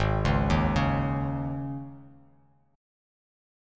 G#sus4 chord